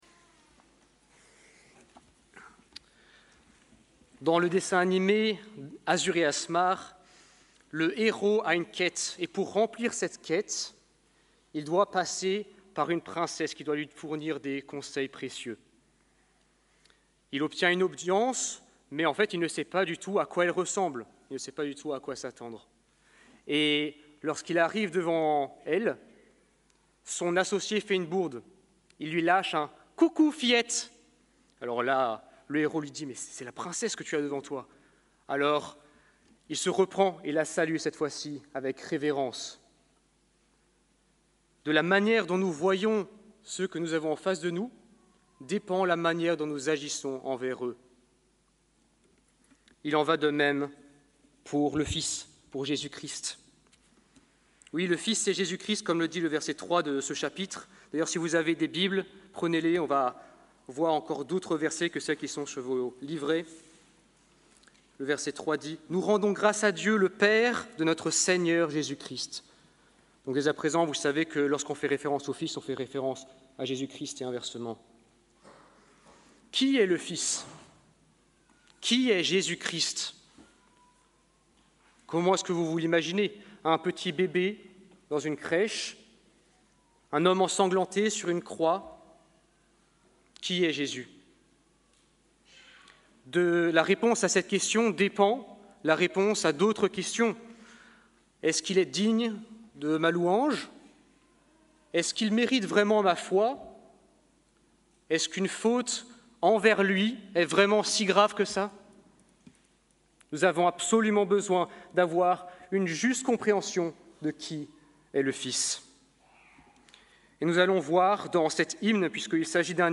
La prééminence de Jésus-Christ requiert toute notre foi | Église Protestante Évangélique de Bruxelles-Woluwe